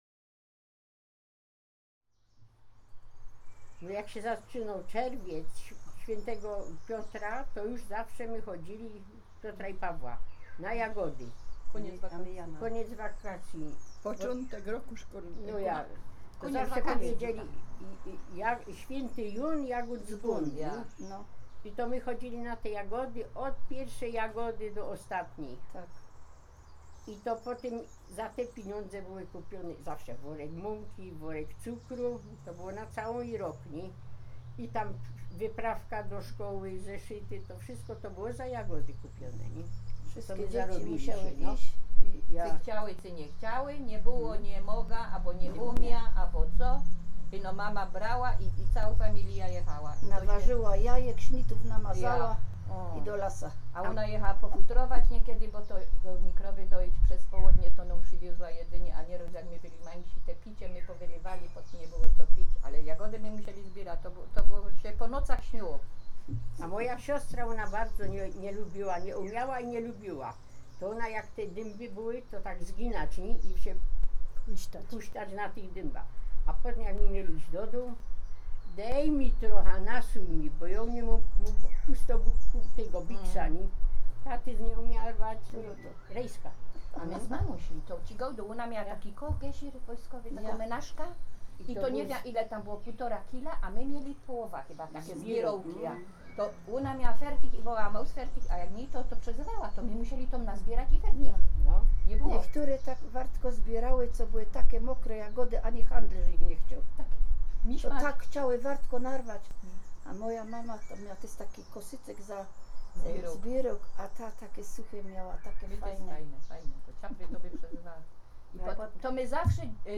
gwara śląska